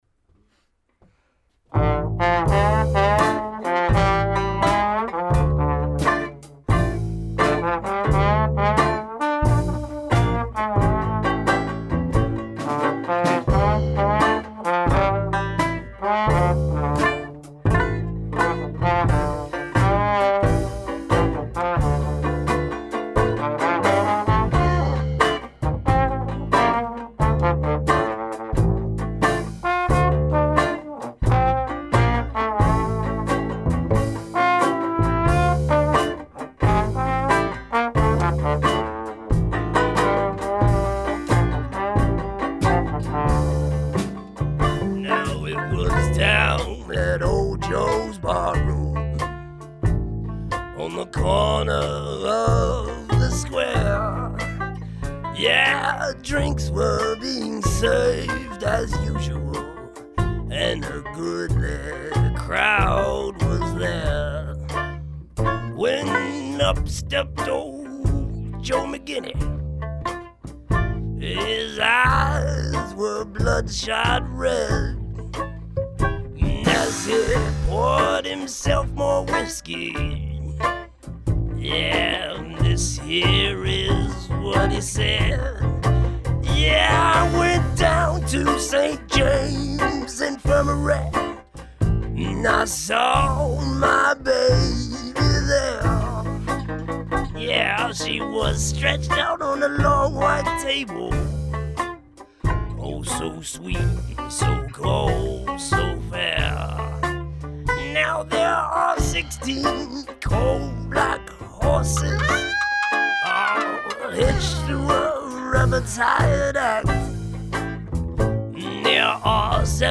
“St. James Infirmary” live on WTMD.